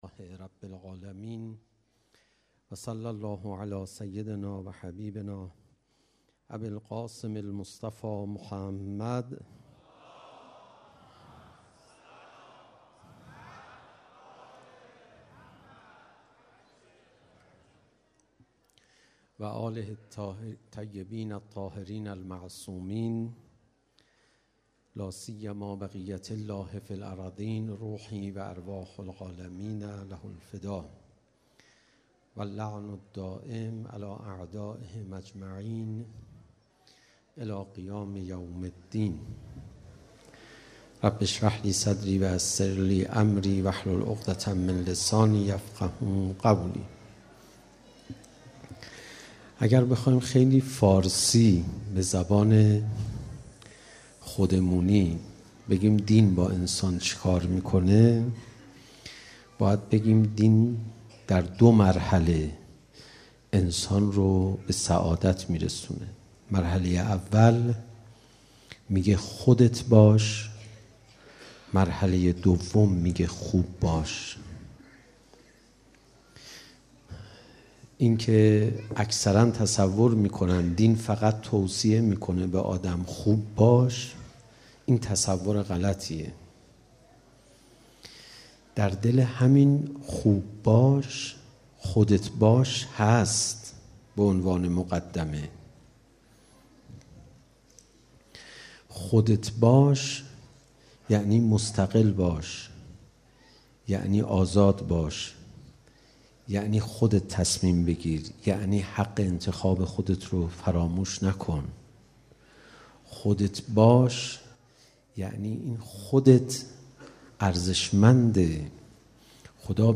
سخنرانی حجت الاسلام و المسلمین علیرضا پناهیان شب اول مراسم عزاداری شهادت صدیقه کبری حضرت فاطمه زهرا سلام الله علیها در مسجد حضرت سیدالشهدا علیه السلام